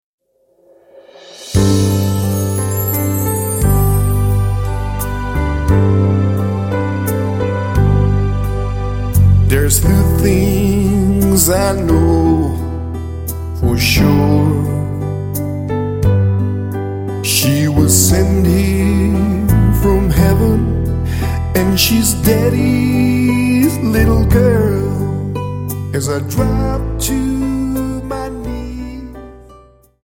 Dance: Slow Waltz 29